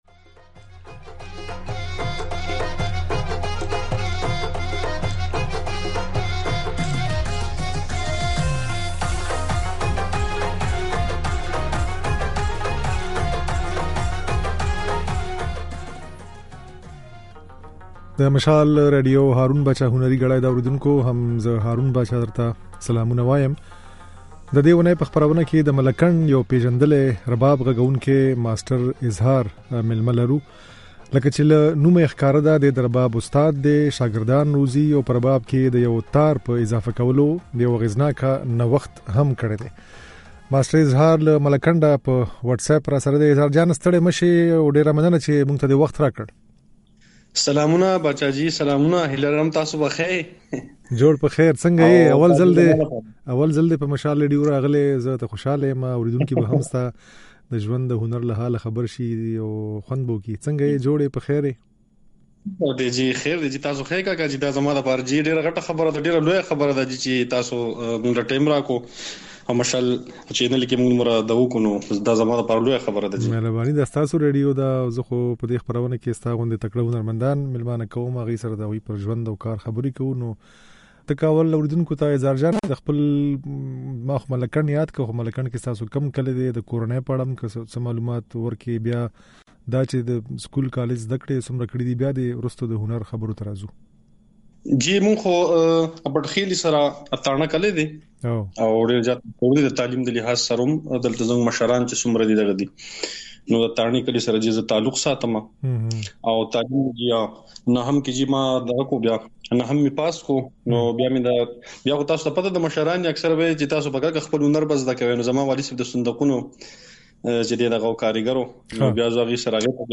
رباب غږوونکي
خبرې او ځينې نغمې يې په خپرونه کې اورېدای شئ.